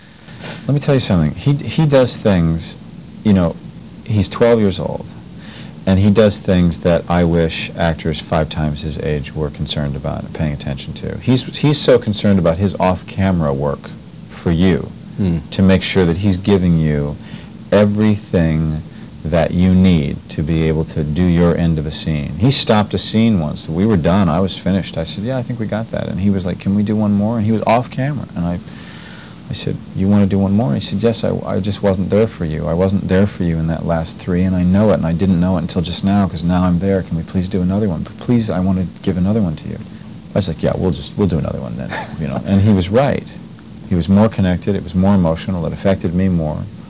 Kevin talks about working with Haley Joel Osment.